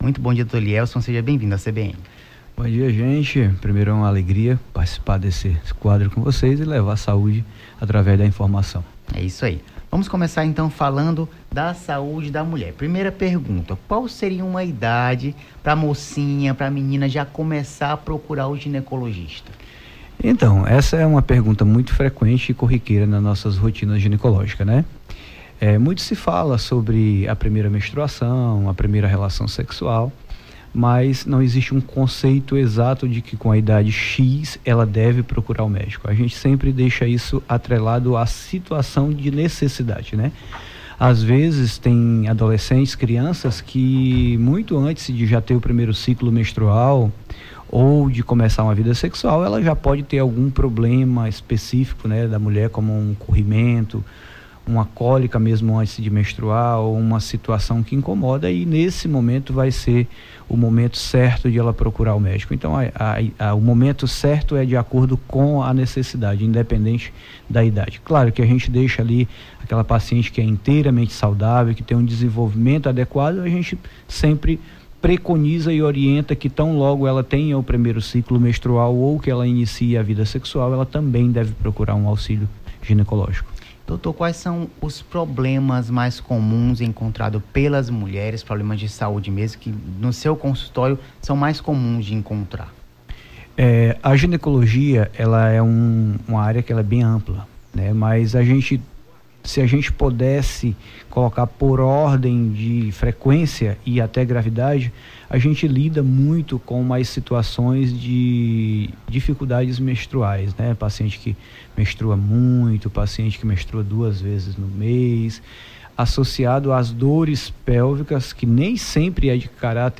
Nome do Artista - CENSURA - ENTREVIISTA (BOM DIA DOUTOR) 20-07-23.mp3